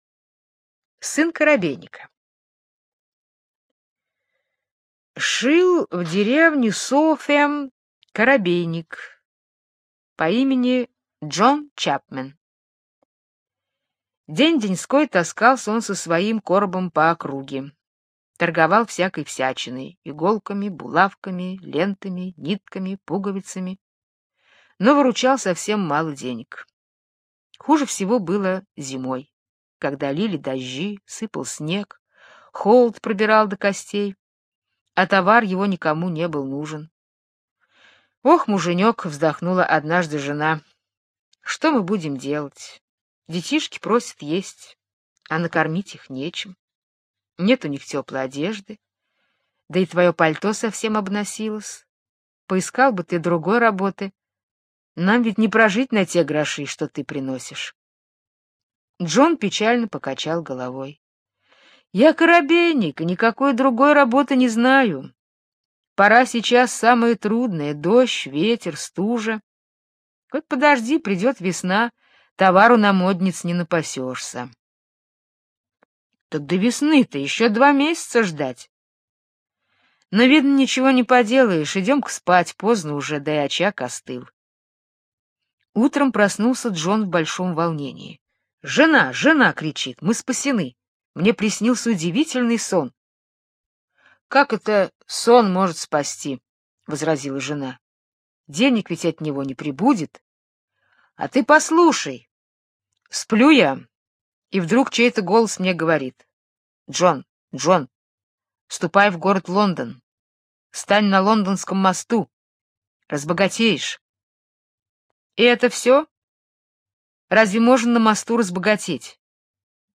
Сон коробейника - британская аудиосказка. Сказка про коробейника, торговля у которого шла очень плохо. Однажды ему приснился вещий сон.